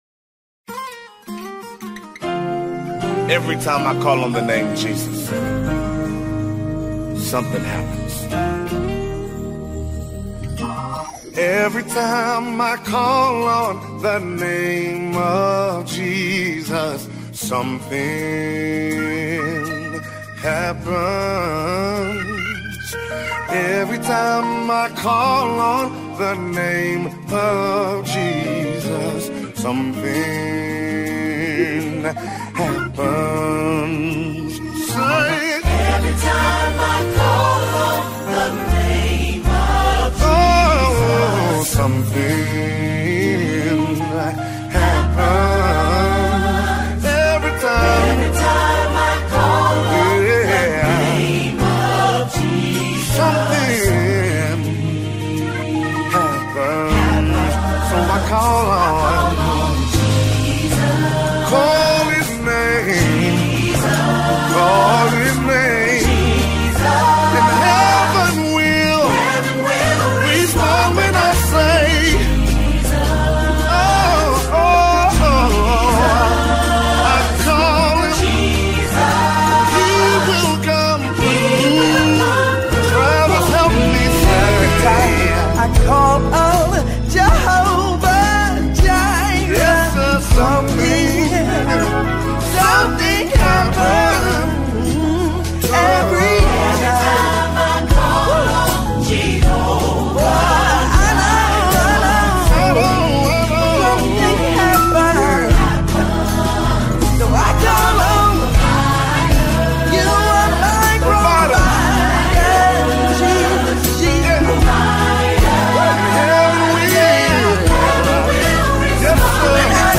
worship single